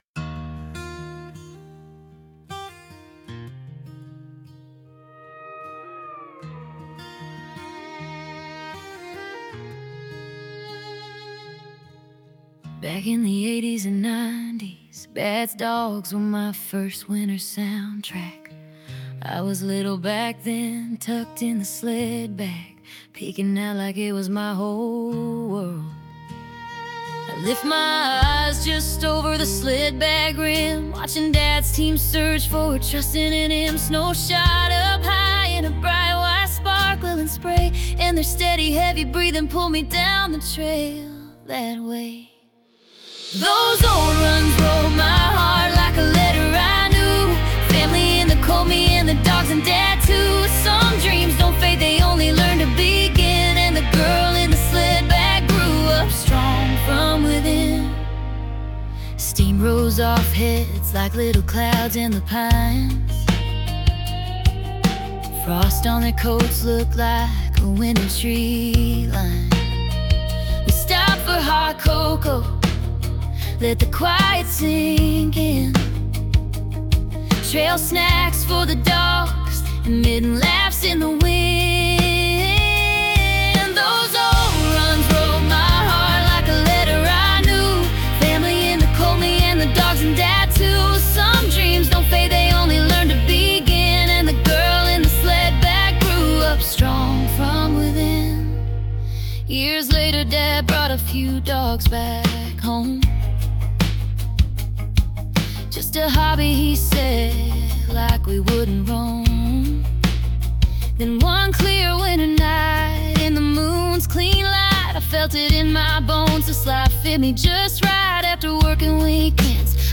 We’ve created this special collection of AI-generated songs to further enrich the stories shared here.